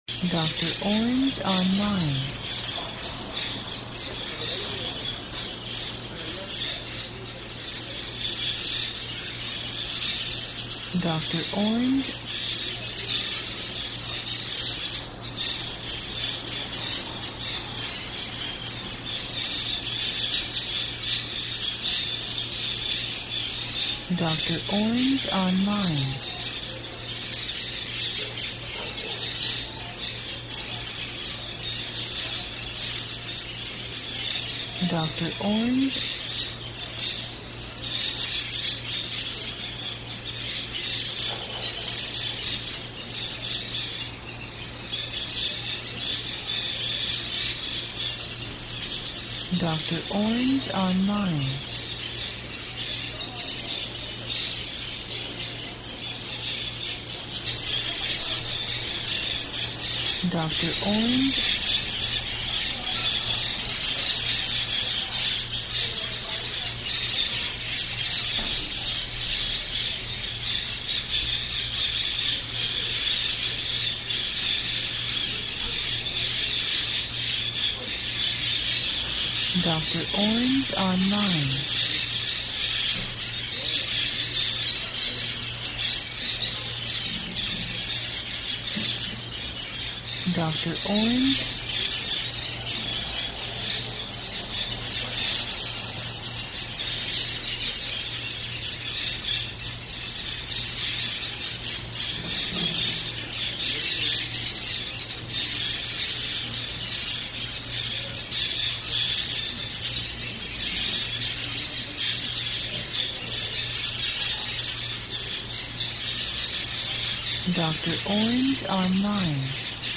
Ambiente Ambisónico Exterior Loros y Papagayos
Archivo de audio AMBISONICO, 96Khz – 24 Bits, WAV.